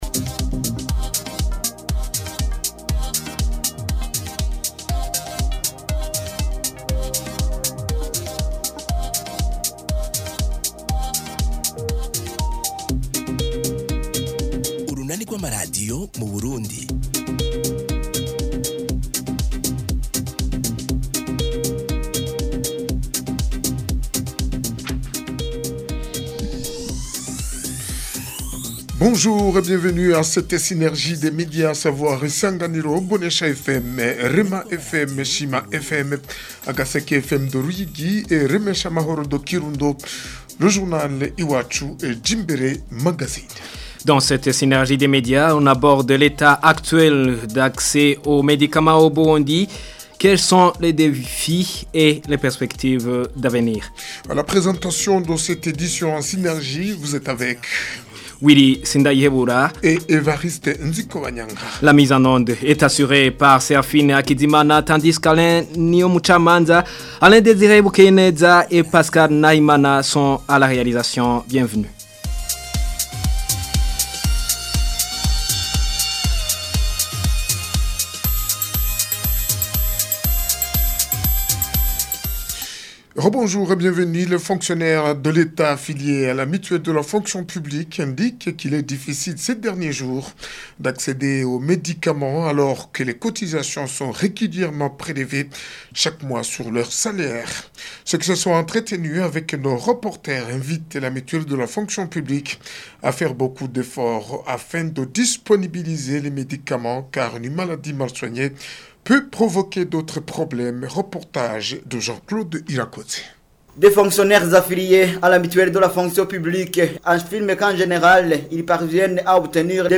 Journal en synergie des médias du 16 octobre 2025